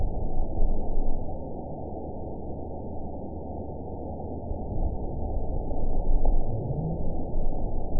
event 921810 date 12/19/24 time 06:20:30 GMT (6 months ago) score 7.73 location TSS-AB03 detected by nrw target species NRW annotations +NRW Spectrogram: Frequency (kHz) vs. Time (s) audio not available .wav